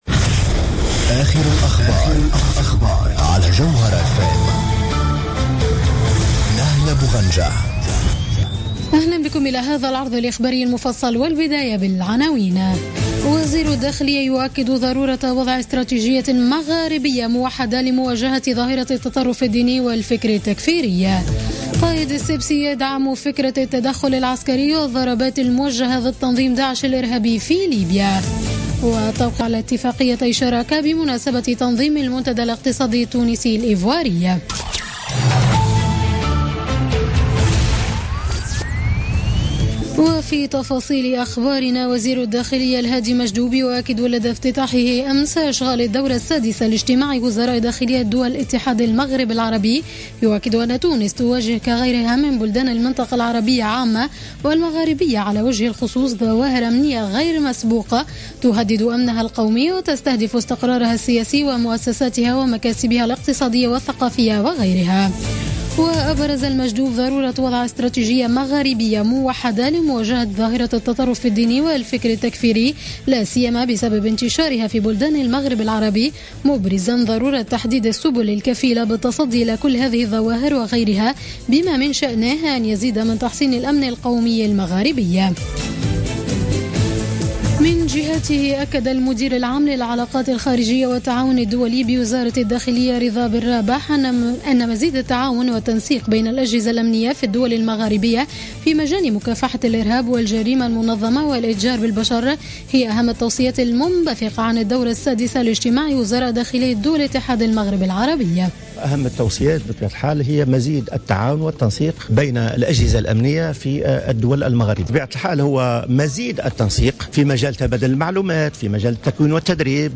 Journal Info 00h00 du mardi 26 avril 2016